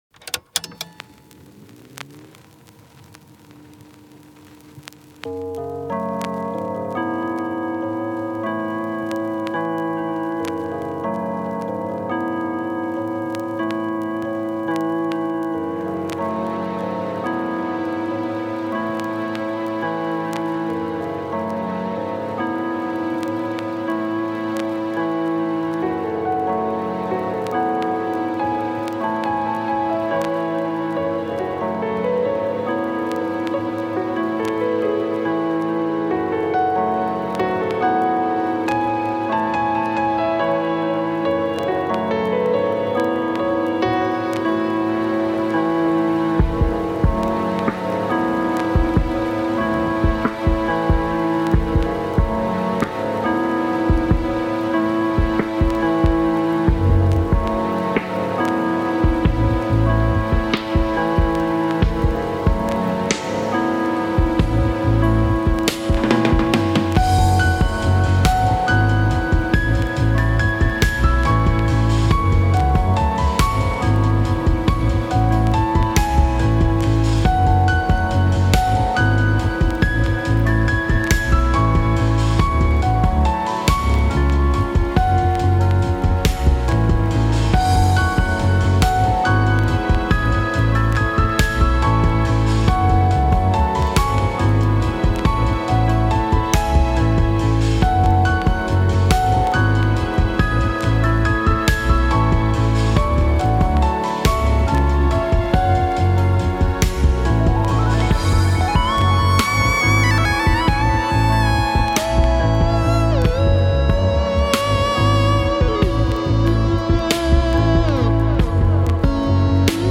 chill